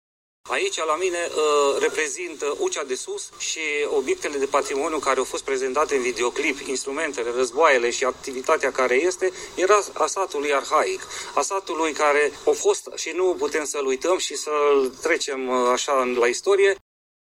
De la pensiuni şi peisaje de poveste, până la mâncăruri delicioase şi meşteşuguri vechi de veacuri, toate le sunt prezentate turiştilor în campania Vara Făgăraşului. Meșteșugarul